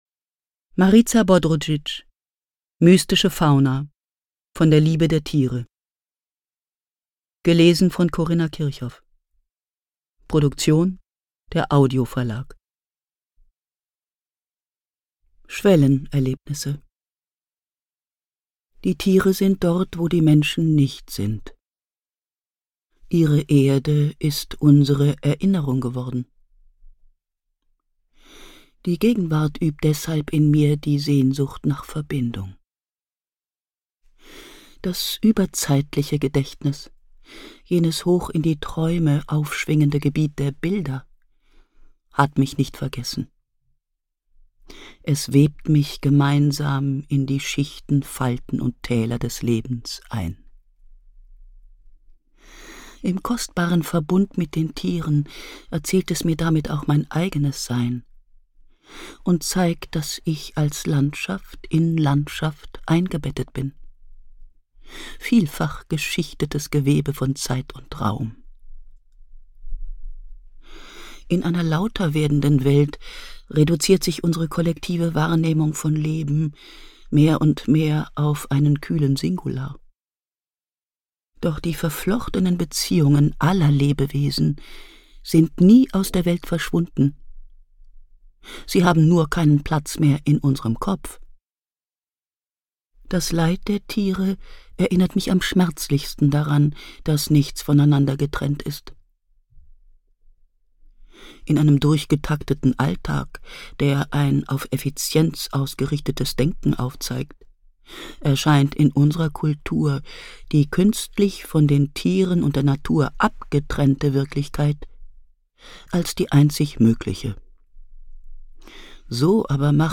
Ungekürzte Lesung mit Corinna Kirchhoff (1 mp3-CD)
Corinna Kirchhoff (Sprecher)